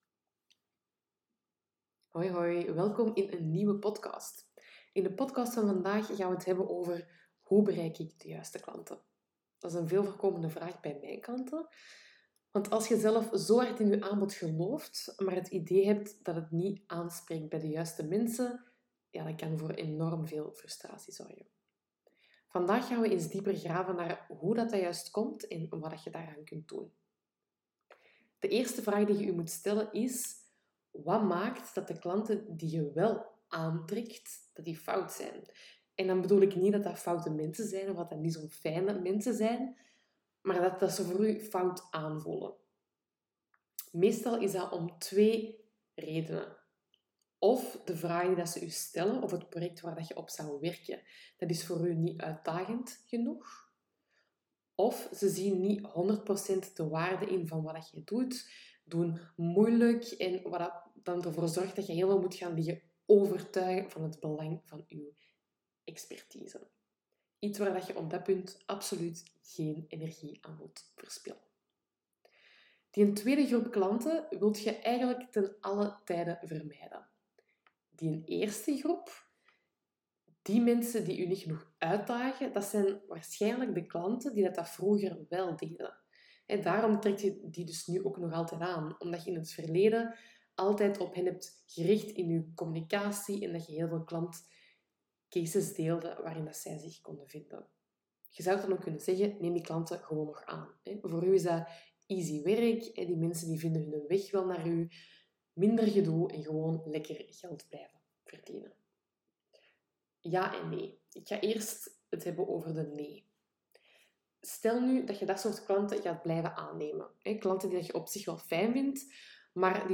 Het kwam er allemaal wat stroef uit, maar mijn boodschap is daarom niet minder sterk of minder duidelijk.
En gaat de vermoeidheid nog een tijd impact blijven hebben op mijn stotteren. Dat gezegd zijnde: in deze aflevering vertel ik waarom je nog niet de juiste klanten aantrekt en welke essentiële eerste stap nodig is om ze wél aan te gaan trekken.